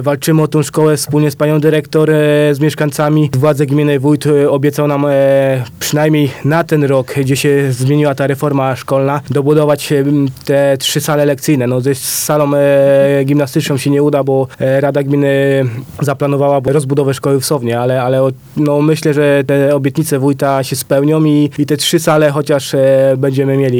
– mówi Mateusz Jarosz, sołtys Strachocina.